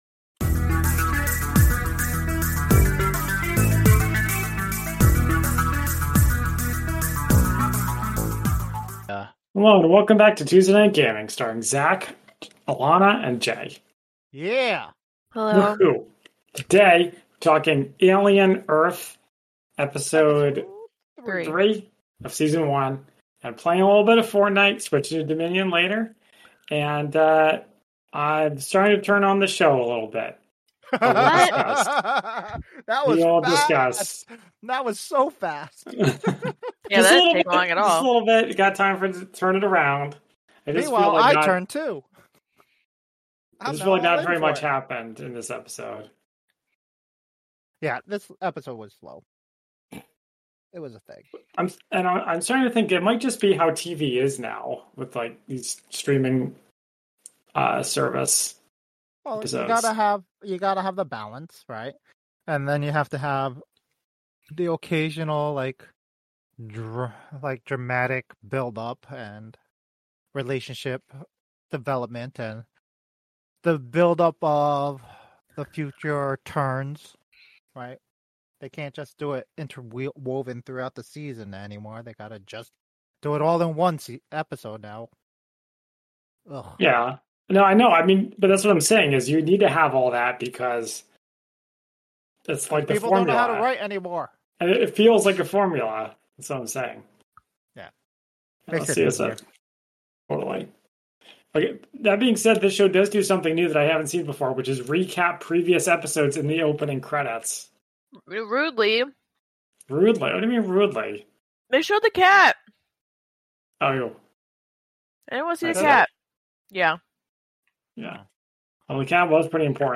Sorry about the audio issues.